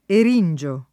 [ er & n J o ]